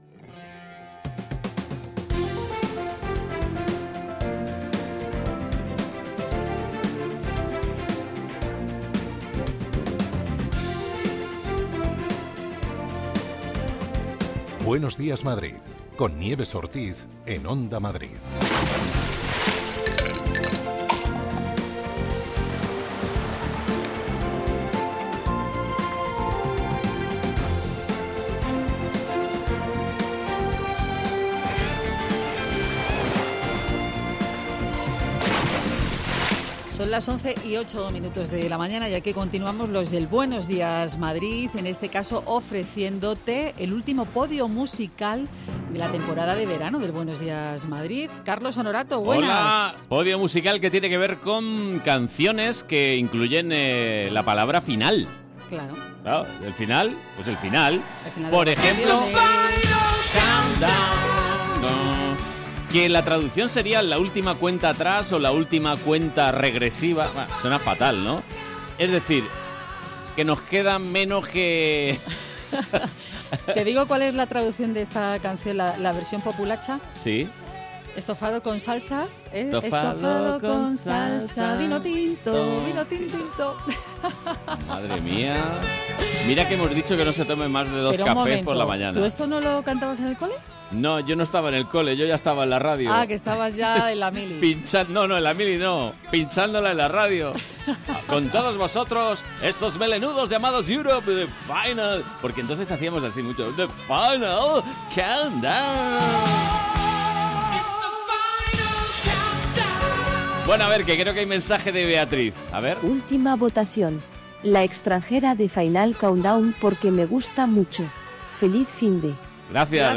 Tres horas más de radio donde se habla de psicología, ciencia, cultura, gastronomía, medio ambiente, consumo.